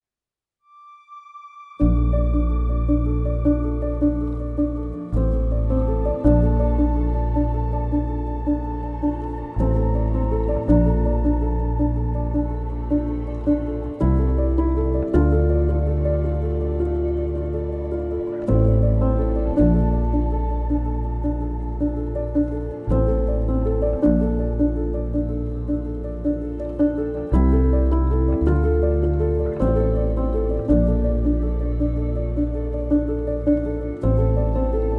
# Ambient